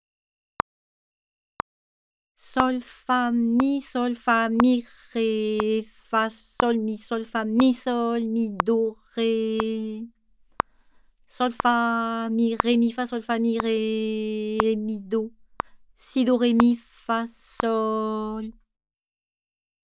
47 - Rythme 01